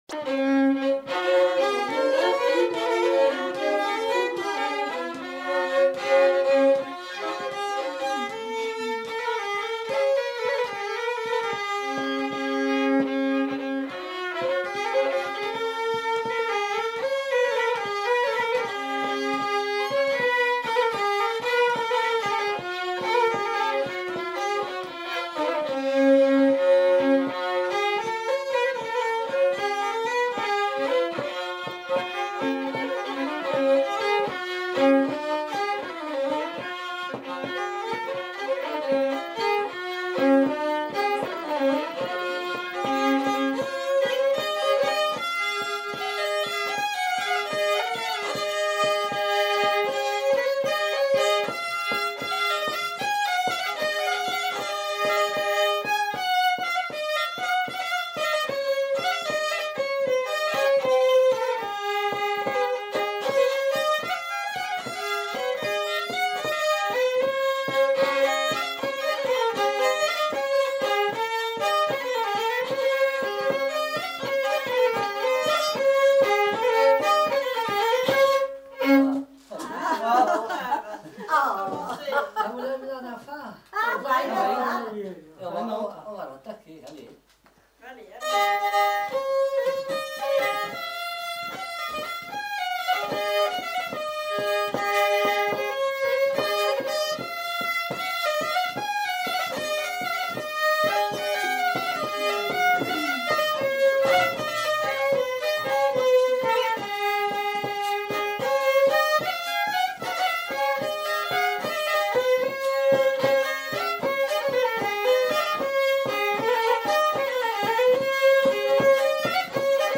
Aire culturelle : Limousin
Lieu : Lacombe (lieu-dit)
Genre : morceau instrumental
Instrument de musique : violon
Danse : valse
Notes consultables : Le second violon est joué par un des enquêteurs.